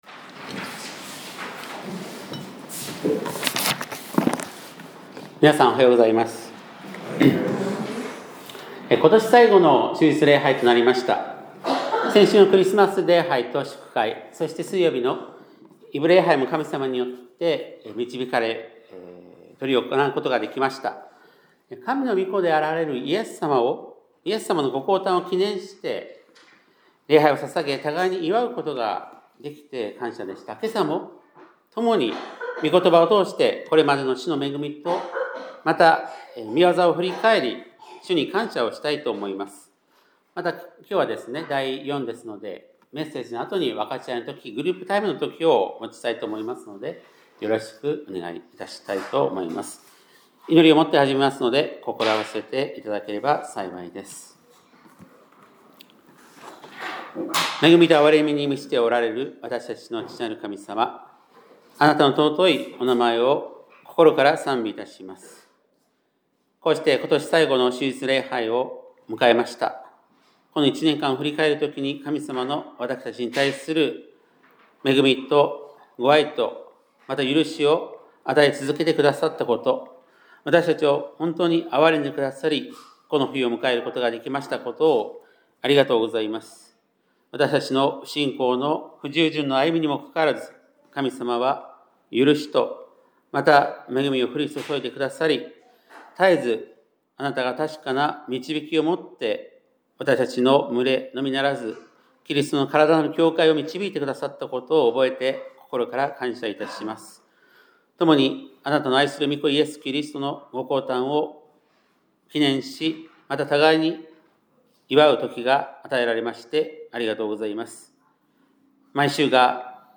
2025年12月28日（日）礼拝メッセージ